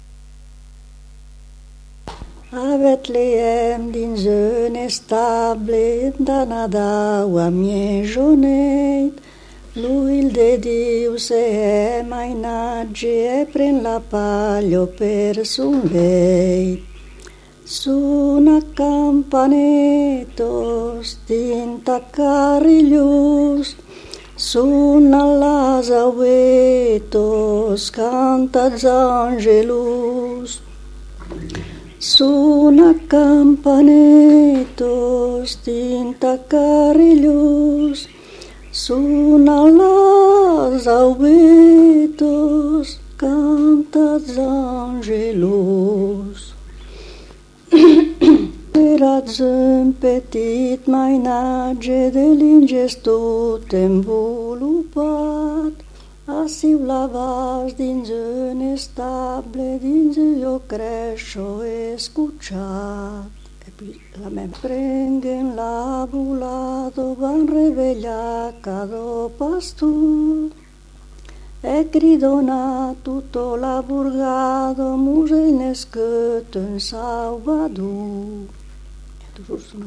Aire culturelle : Savès
Genre : chant
Effectif : 1
Type de voix : voix de femme
Production du son : chanté
Classification : noël
Notes consultables : L'interprète ne reprend pas toujours le refrain.
• [enquêtes sonores] Répertoire de chants du Savès